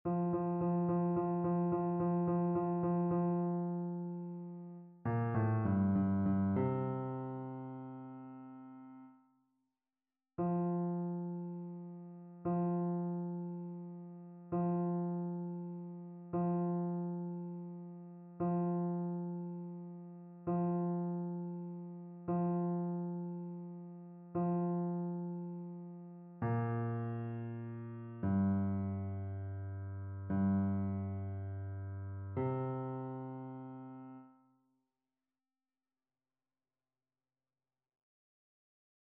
Basse
annee-abc-temps-de-noel-nativite-du-seigneur-psaume-96-basse.mp3